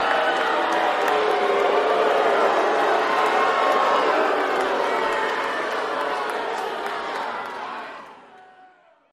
Crowd Boos During Fight